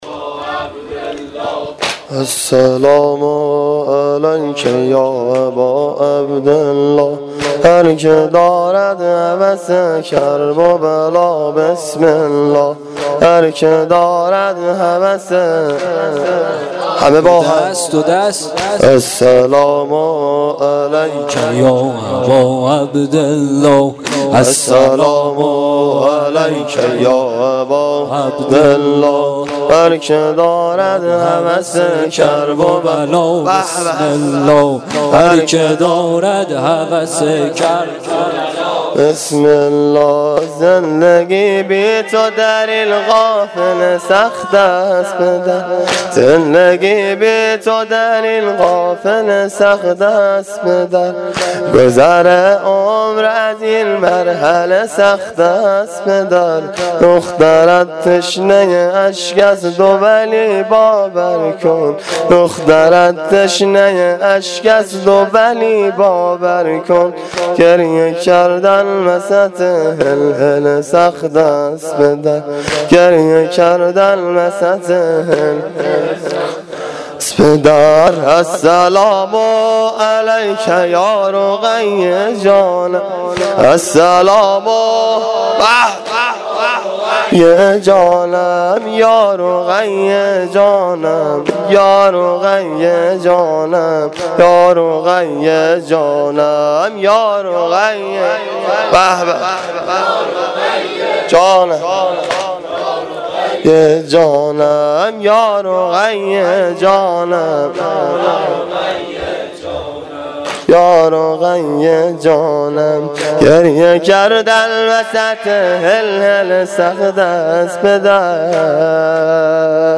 واحد تند شب سوم محرم الحرام 1395